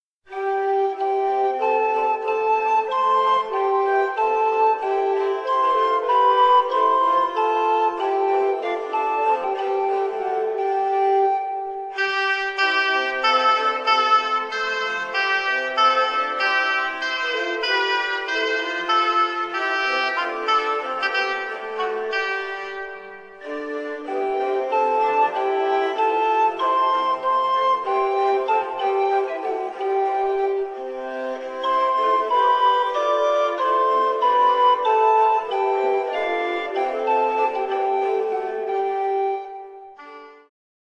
Współczesne prawykonania średniowiecznych utworów z Wrocławia, Cieszyna, Środy Śląskiej, Głogowa, Brzegu, Henrykowa, Żagania, kompozytorów anonimowych, Nicolausa Menczelliniego, hymny i sekwencja o św.
kontratenor, lutnia
fidel